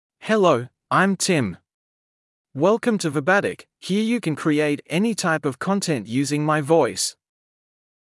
MaleEnglish (Australia)
TimMale English AI voice
Tim is a male AI voice for English (Australia).
Voice sample
Tim delivers clear pronunciation with authentic Australia English intonation, making your content sound professionally produced.